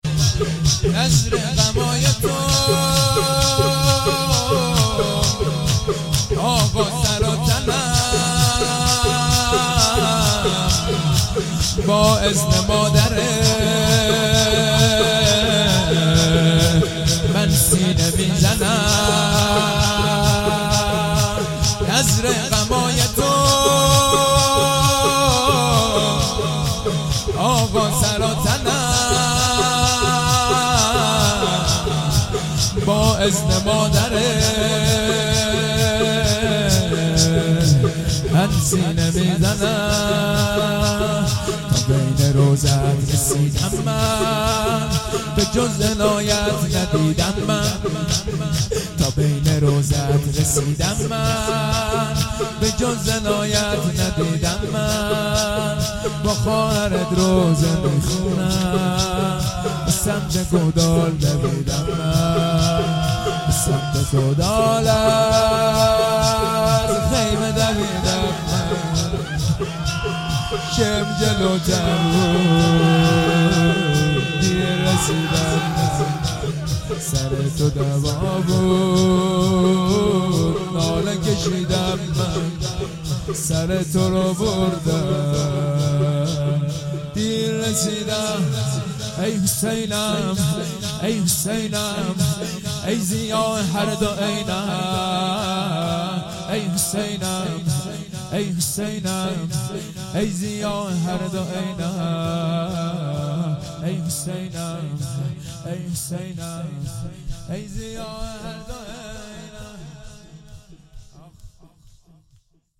شب دوم فاطمیه دوم 98- هیئت الزهرا(س) - شور اول